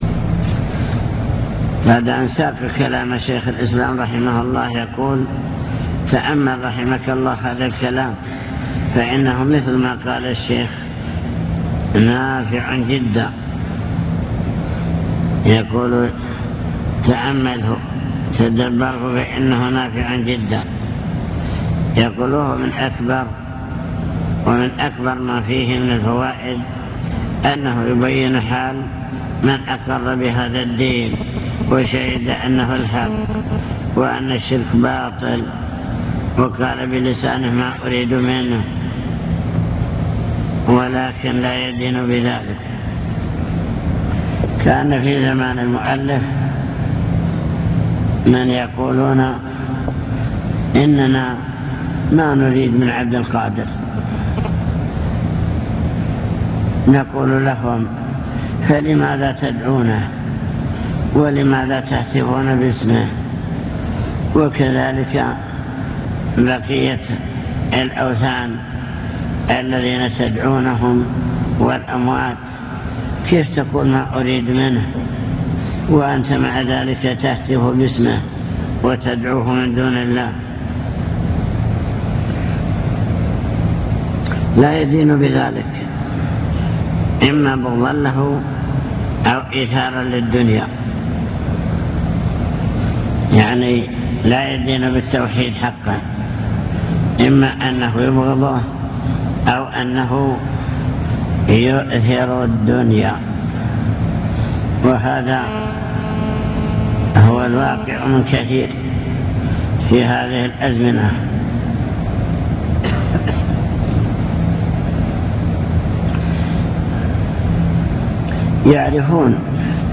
المكتبة الصوتية  تسجيلات - كتب  شرح كتاب مفيد المستفيد في كفر تارك التوحيد